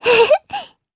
Lilia-Vox_Happy1_jp.wav